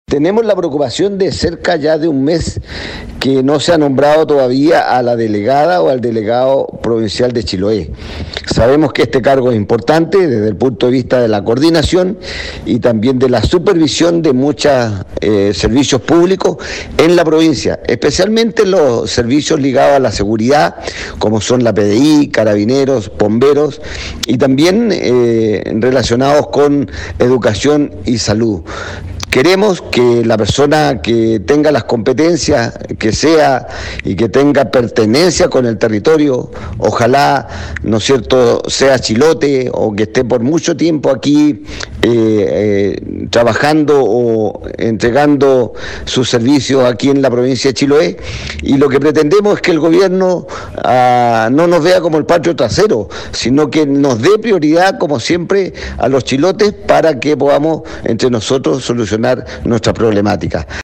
En palabras del parlamentario es una muestra de abandono del Gobierno hacia la provincia de Chiloé que mantiene muchos temas pendientes en materia ambiental, de salud, educación, seguridad y conectividad, entre otros: